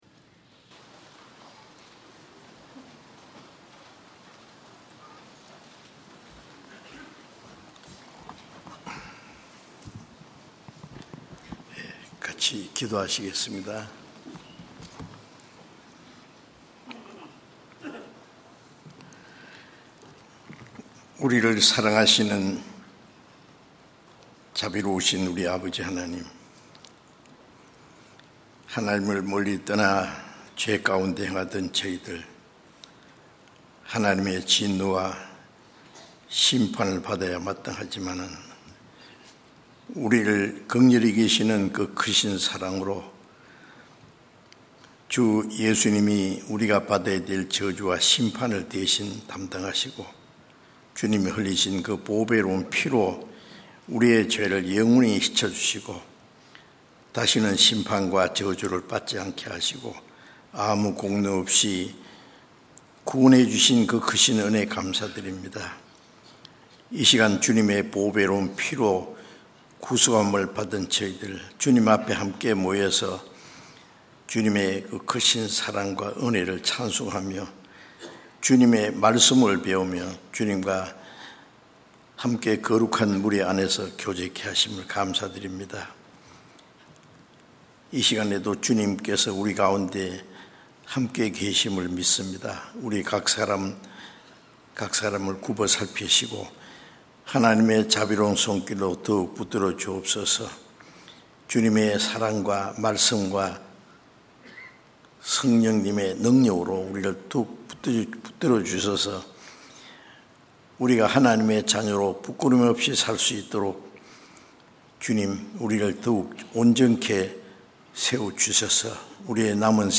주일설교수요설교 (Audio)